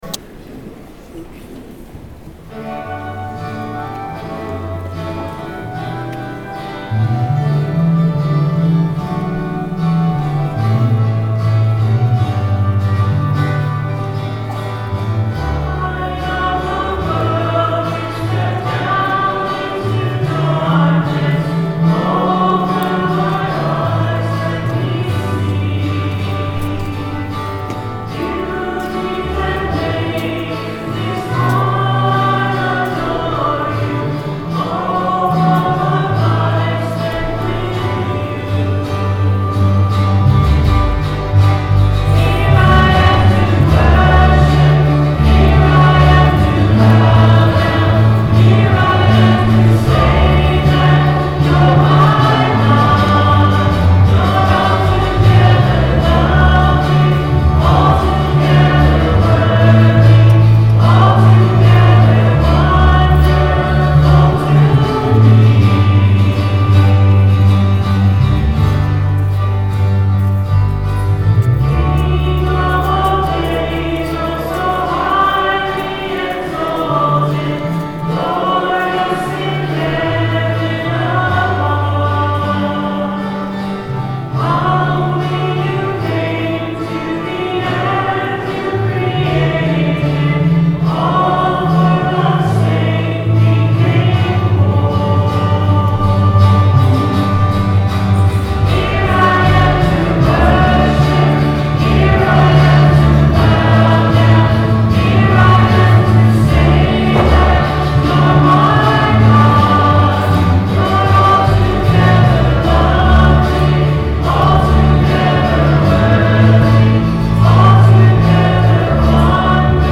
11/01/09 10:30 Mass Recording of Music - BK1030
Music for Mass